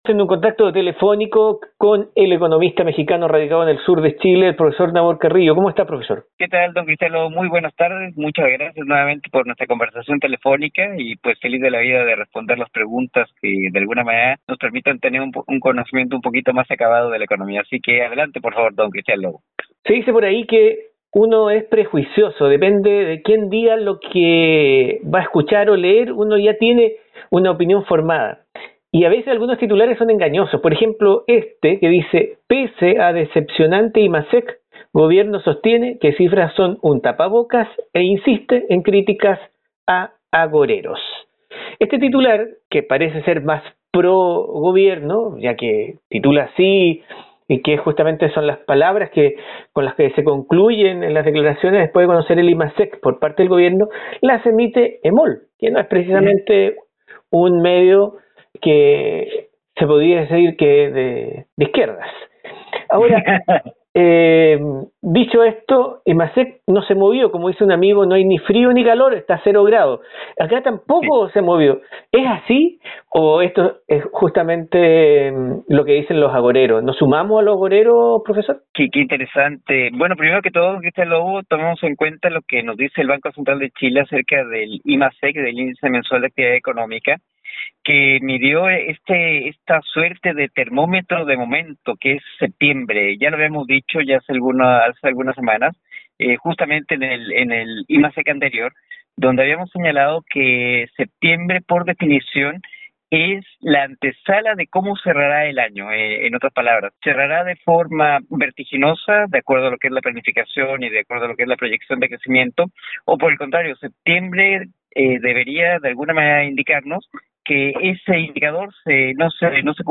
En una conversación telefónica con el economista mexicano residente en el sur de Chile